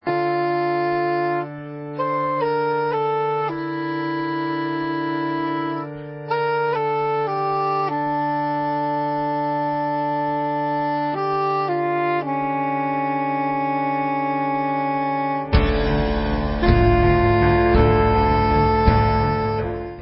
Brass punk from finland